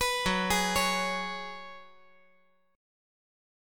F#sus2sus4 Chord
Listen to F#sus2sus4 strummed